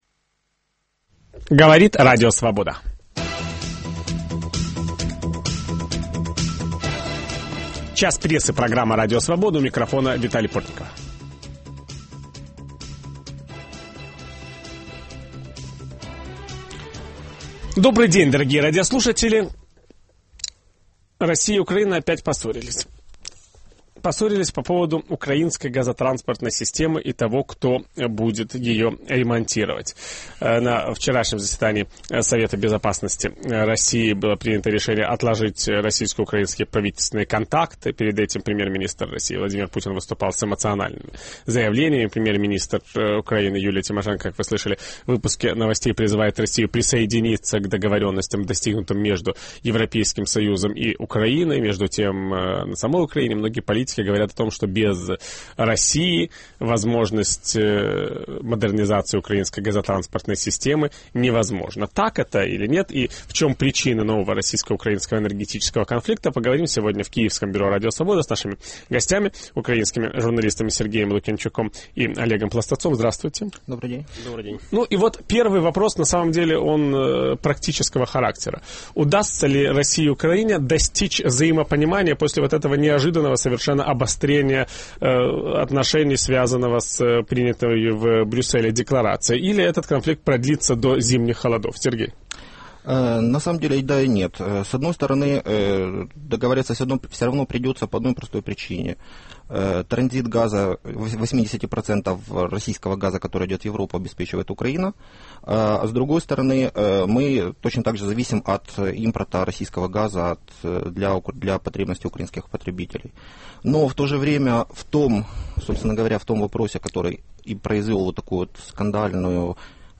Об этом Виталий Портников беседует со своими гостями.